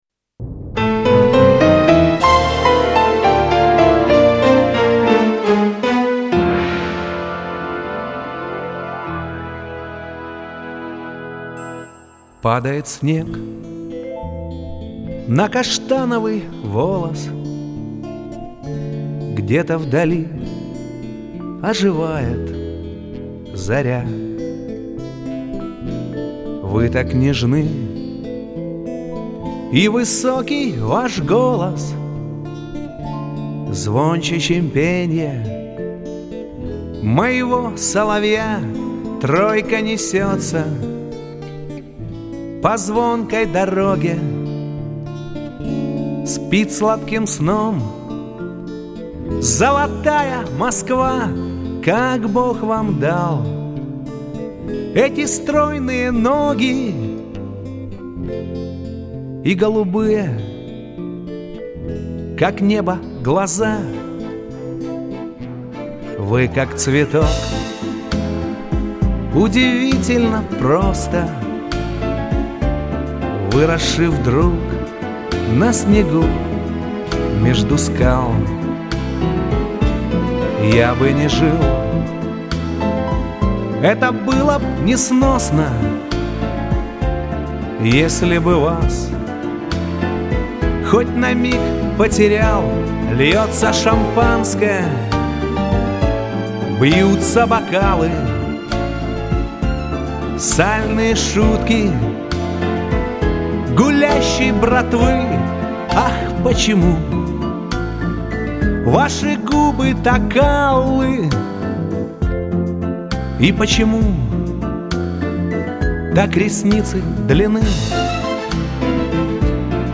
очень мелодичной и лиричной песней